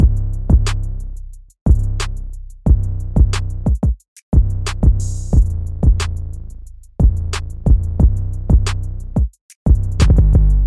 糖果漆808和大鼓
标签： 180 bpm Hip Hop Loops Drum Loops 1.79 MB wav Key : Unknown FL Studio
声道立体声